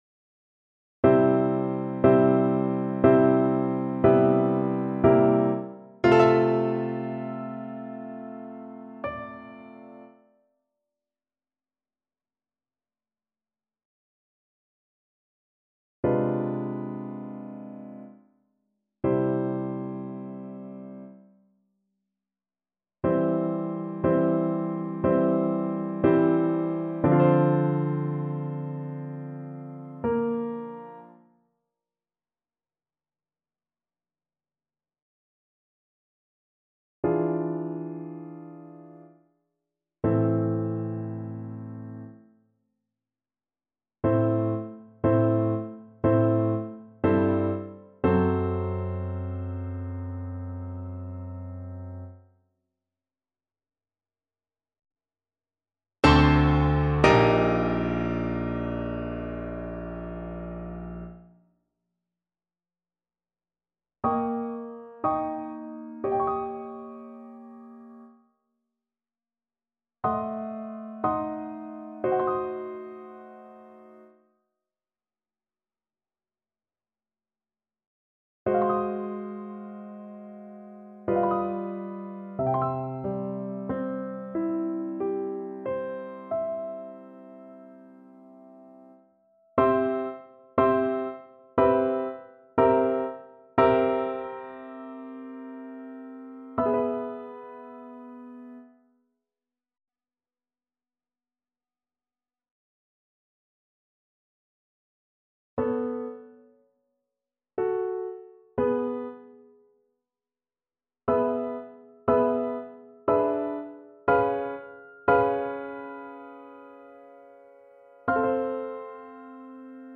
3/4 (View more 3/4 Music)
~ = 60 Langsam, leidenschaftlich
Classical (View more Classical Flute Music)